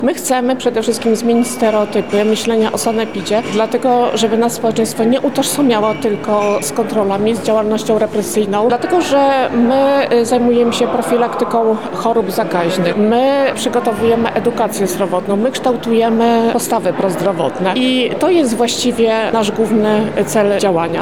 W Lubelskim Urzędzie Wojewódzkim odbył się Dzień Otwarty Inspekcji Sanitarnej.
O szczegółach mówi Renata Kunc-Kozioł, Państwowy Powiatowy Inspektor Sanitarny w Lublinie: